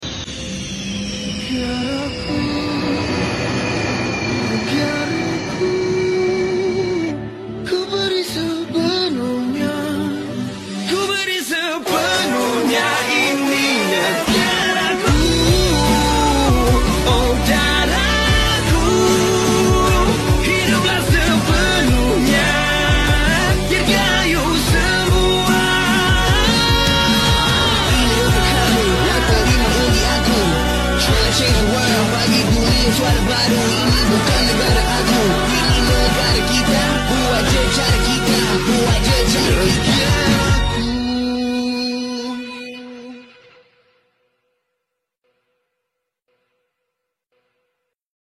Persembahan Udara Merdeka Flypast 68 sound effects free download
Persembahan Udara Merdeka Flypast 68 Putrajaya & Melaka Hari Ini..